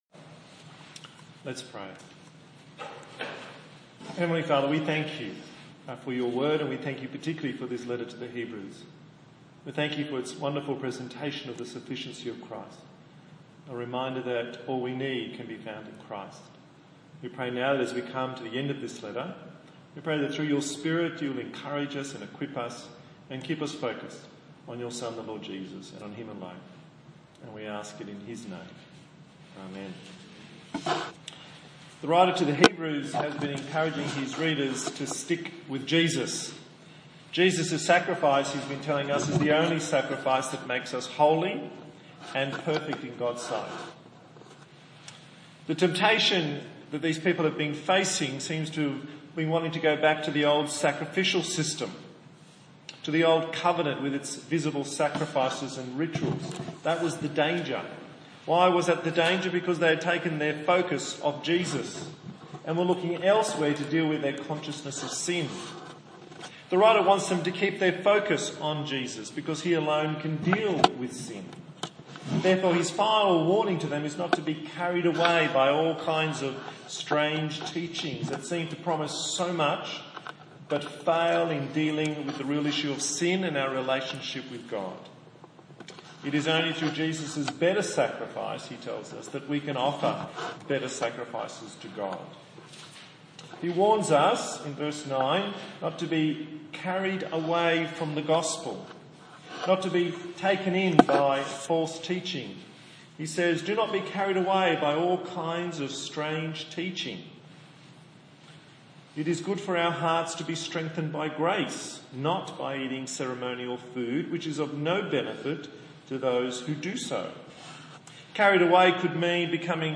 A sermon in the series on the book of Hebrews